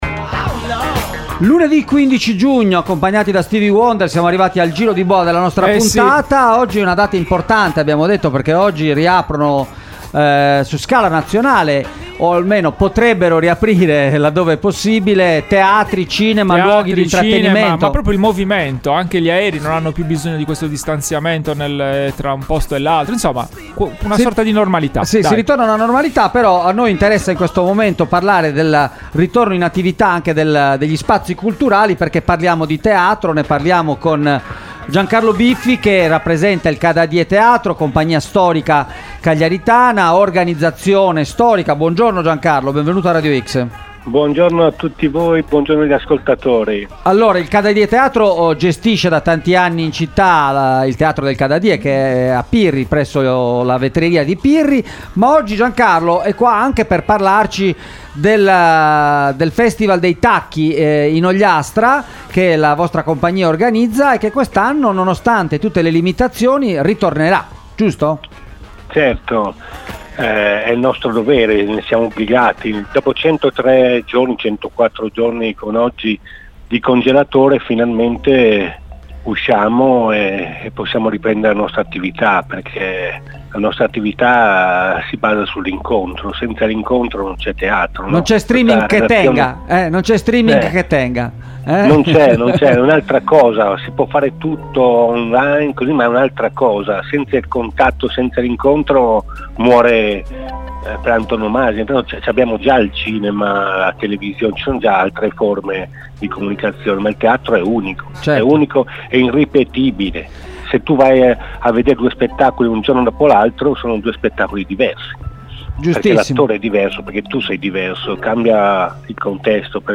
Festival dei Tacchi ASCOLTA L’INTERVISTA https